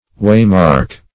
Waymark \Way"mark`\, n. A mark to guide in traveling.